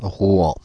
Ääntäminen
Ääntäminen France (Paris): IPA: /ʁwɑ̃/ Haettu sana löytyi näillä lähdekielillä: ranska Käännöksiä ei löytynyt valitulle kohdekielelle.